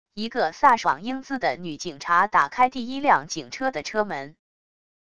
一个飒爽英姿的女警察打开第一辆警车的车门wav音频